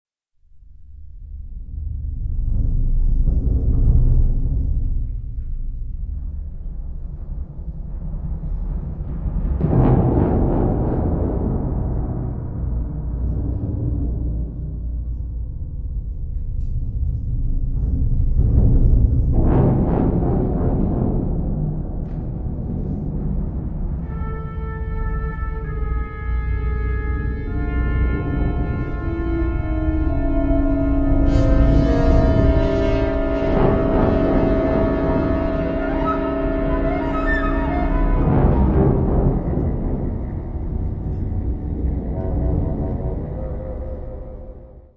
Category Concert/wind/brass band
Subcategory Contemporary Wind Music (1945-present)
Instrumentation Ha (concert/wind band)